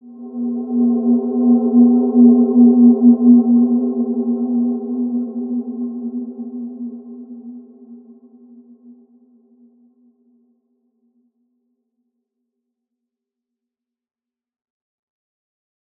Wide-Dimension-B2-mf.wav